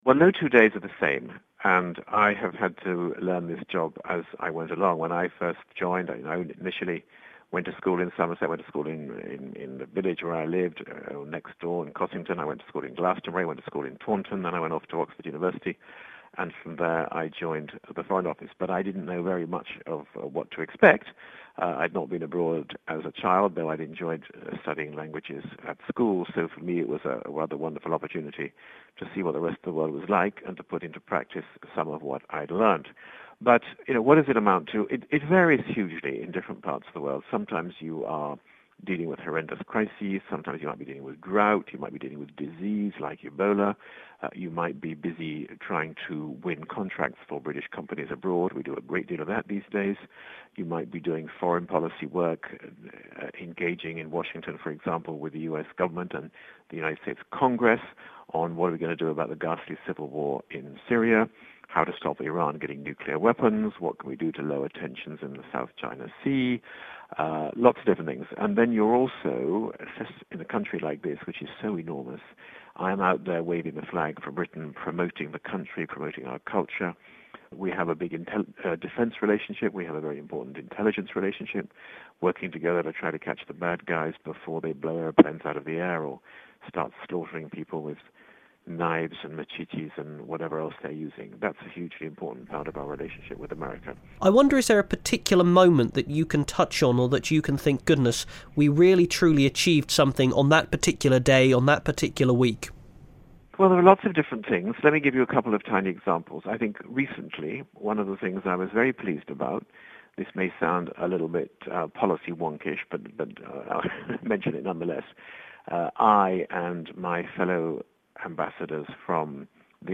The British Ambassador to the United States talks to BBC Somerset